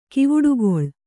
♪ kivuḍugoḷ